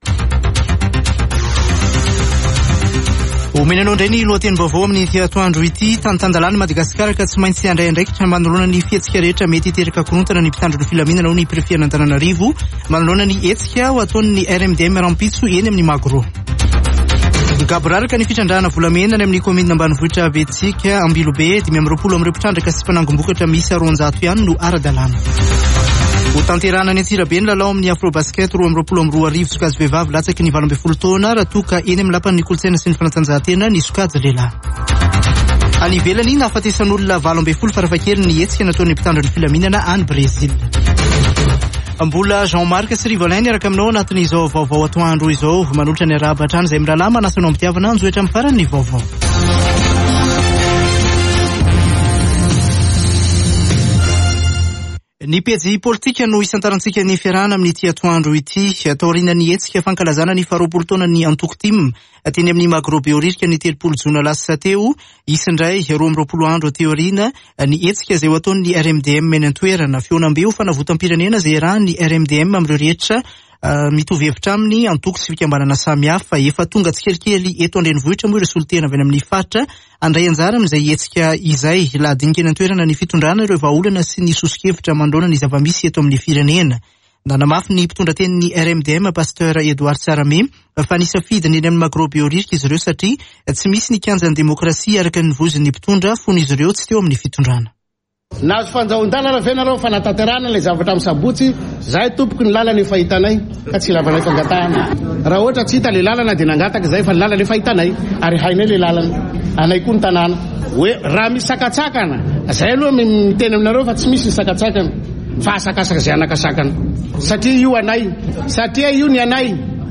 [Vaovao antoandro] Zoma 22 jolay 2022